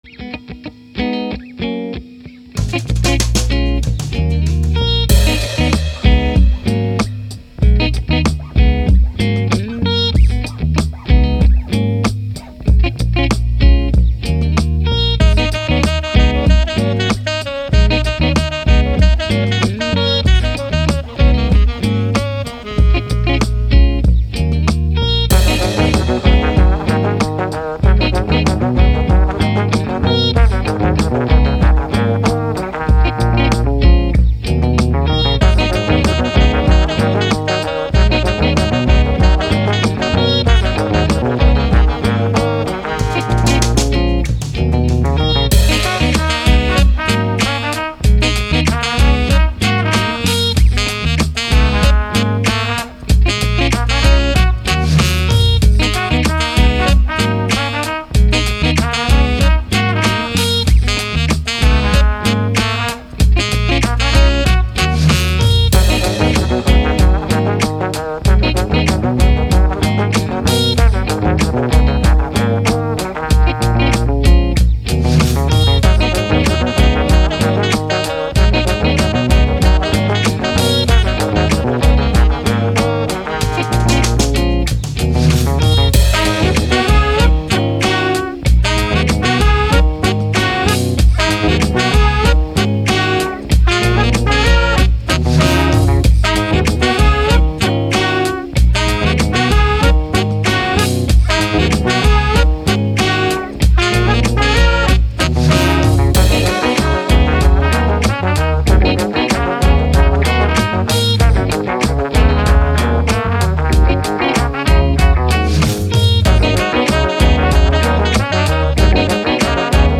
Hip Hop, Jazz, Funk, Brass, Positive, Cool